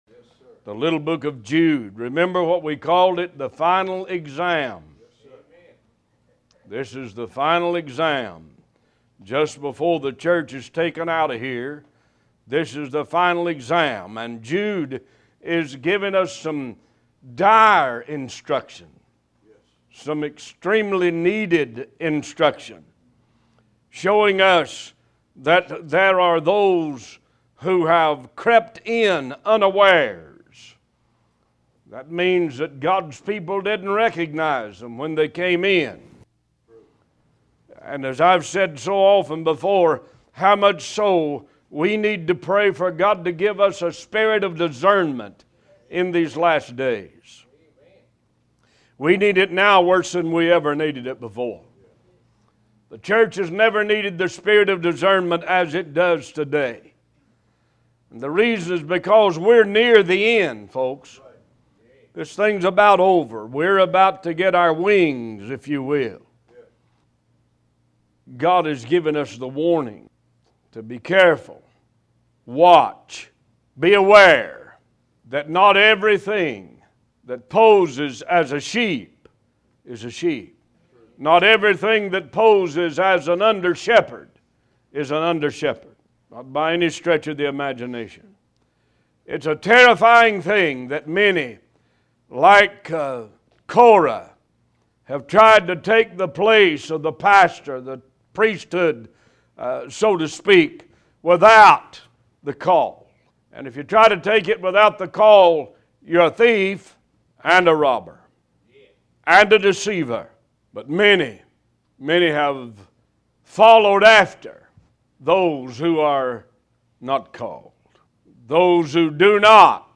Talk Show Episode
New Sermons published every Sunday and Wednesday at 11:30 AM EST